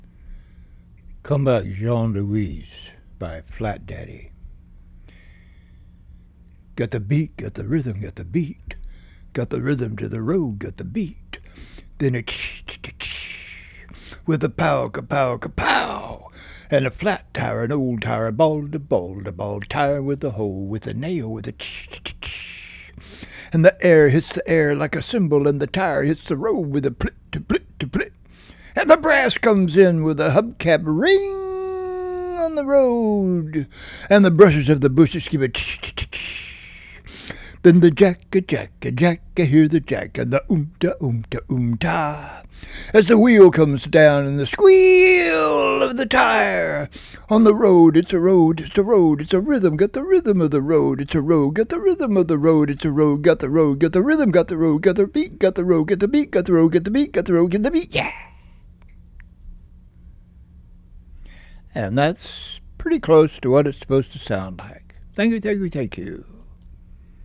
In memory oj Jack Kerouac, whose real name was Jean-Louis This is better if you speak it with a rhythm, a beat, and it gets faster toward the end, like a car taking off!
I really like this beat-nik vibe.